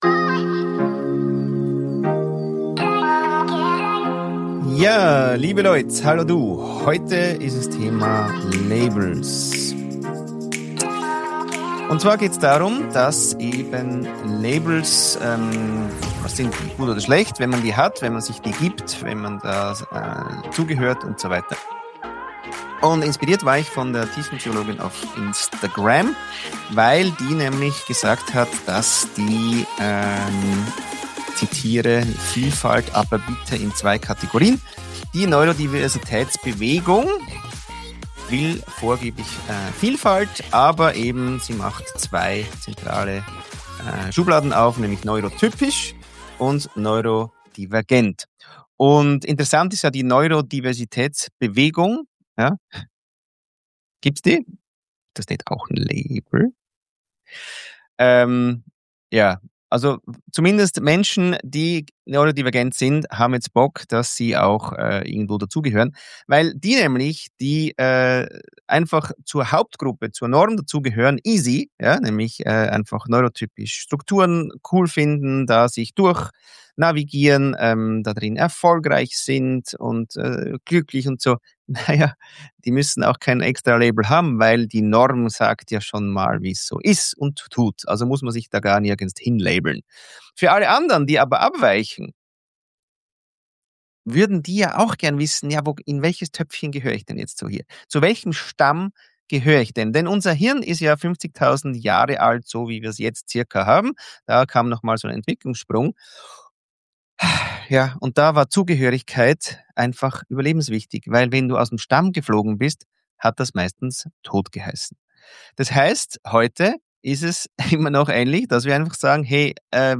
In dieser Solo-Episode des Pirate Talks